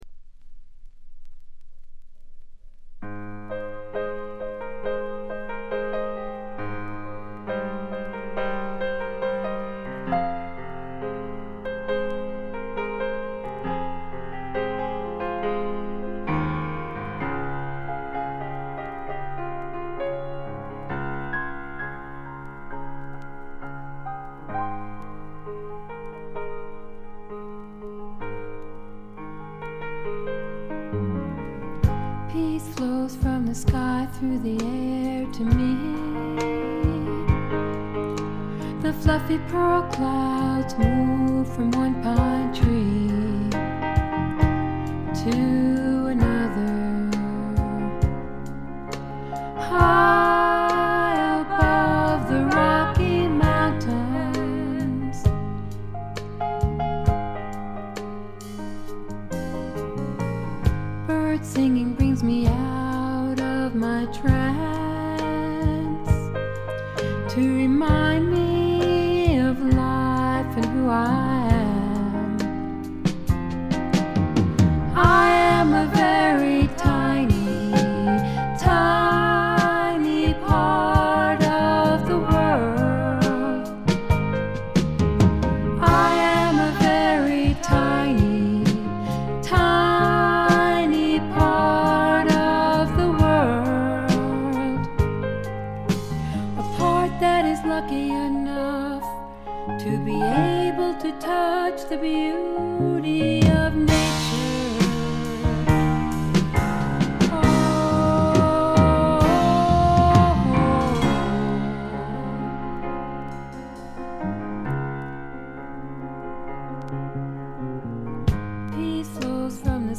軽微なチリプチ少々。
少し舌足らずな声で歌う楚々とした魅力が最高です。
基本は控えめなバックが付くフォークロック。
試聴曲は現品からの取り込み音源です。